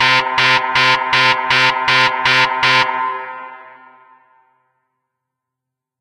nuke_warning.ogg